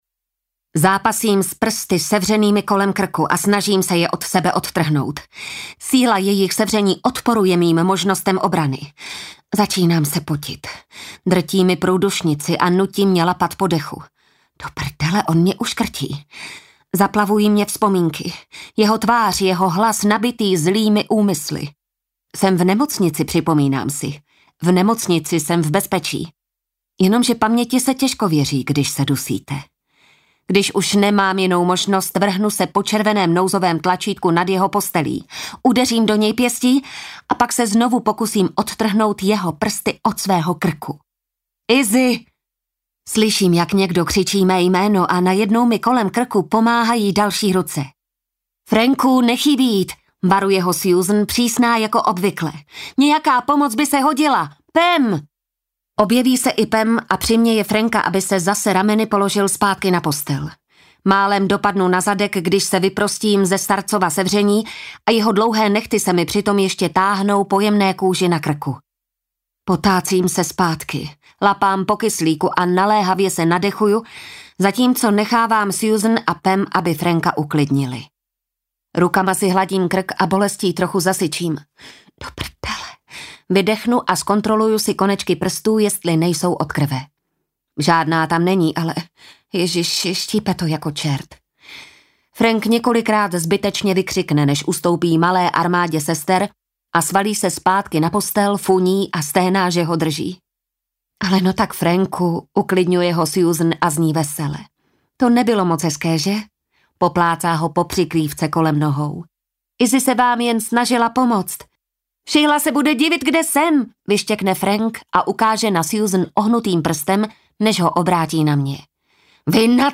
Audiokniha pre dospelých
Žáner: Romantika, erotika, Román